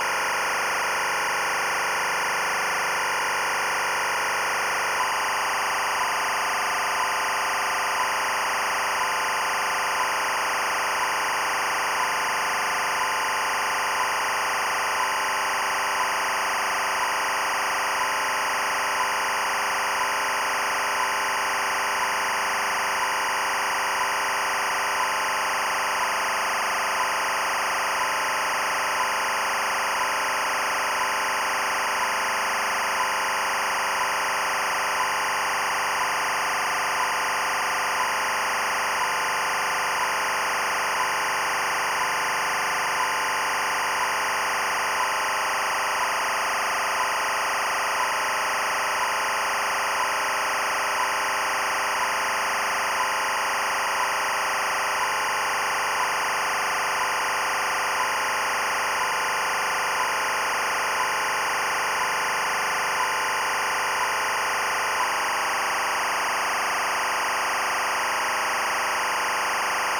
The other track has 1KHz bursts one frame long, once per second stating at 01:00:00:00 and ending at 01:01:00:00. The bursts are positioned exactly at the start of each second.